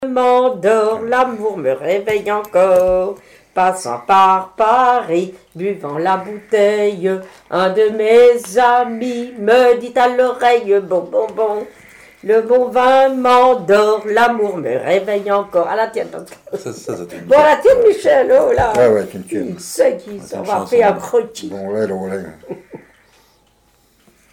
circonstance : bachique
Pièce musicale inédite